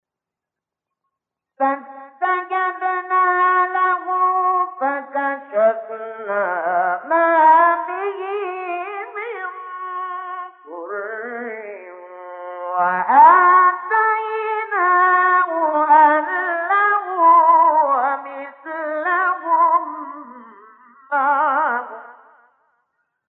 گروه شبکه اجتماعی: مقاطعی صوتی از تلاوت قاریان برجسته مصری ارائه می‌شود.